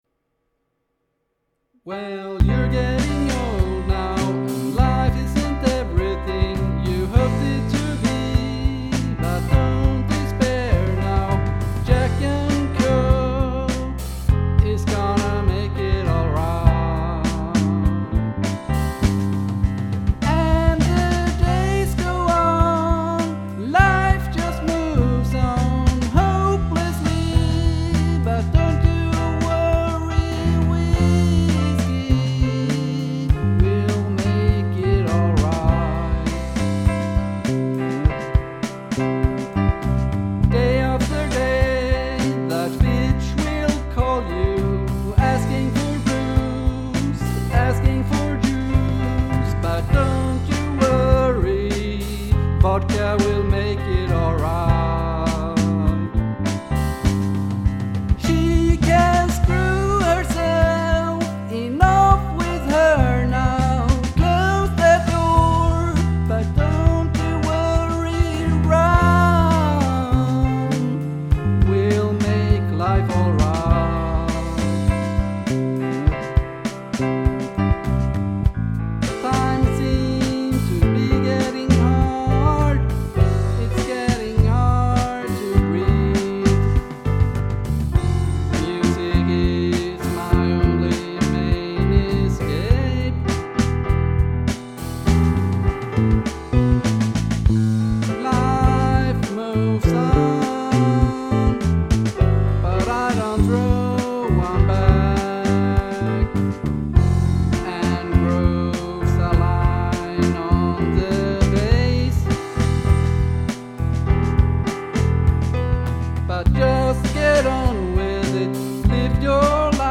Team Cocking Funk
singing about beer instead of penii.
The backing track was great.
The timing on the backing track was off tempo (a couple spots there was some tempo phasing between the vocals, instrumentation and drums) in a couple of spots, be careful!
I'm not sure if you didn't warm up previous to recording, but the tuning in the vocals in some spots is absolutely atrocious. It's especially bad when you have unison notes with the bass.
Seriously, its drunk open mic night at the local bar.